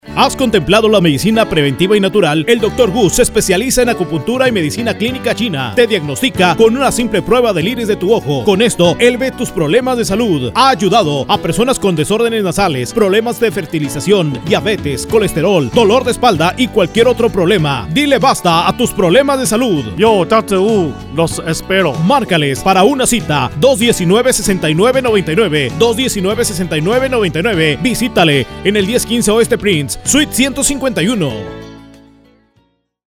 Spanish Radio Commercial FM 101.3 ,FM92.1